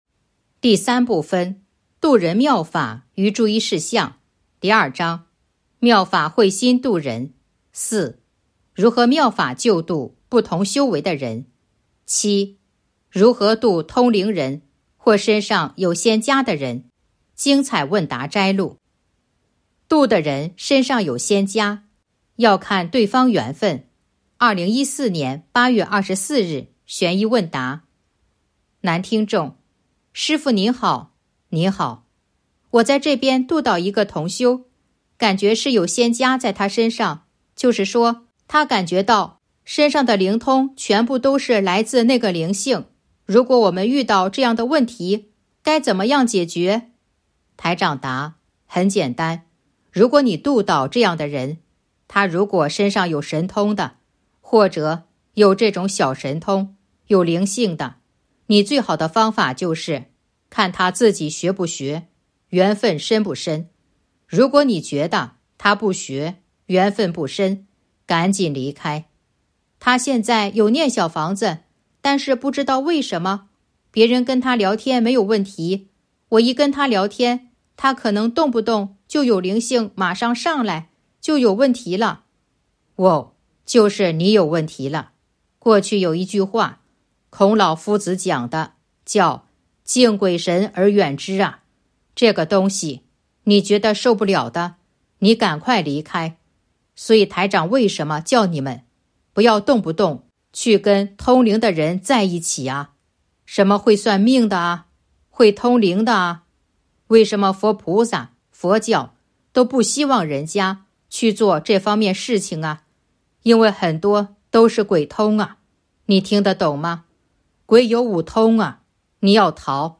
034.（七）如何度通灵人或身上有仙家的人精彩问答摘录《弘法度人手册》【有声书】